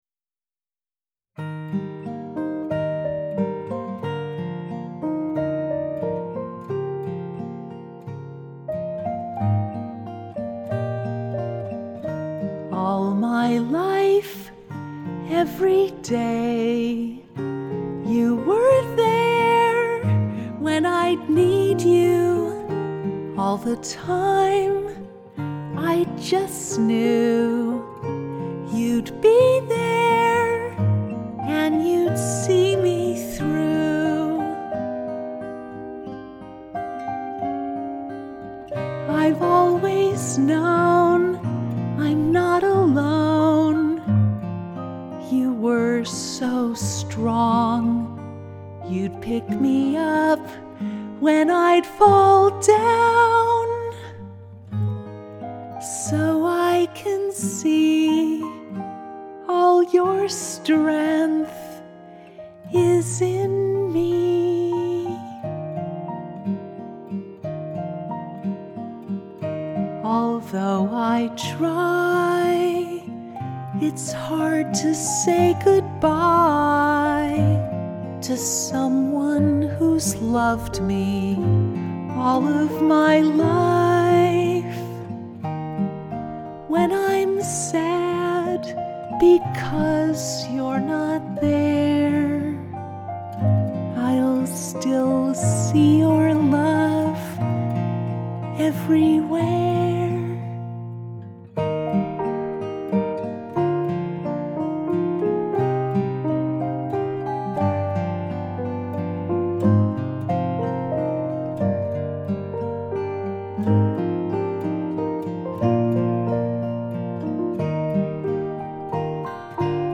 you-were-there-acoustic-9-10-18-p.mp3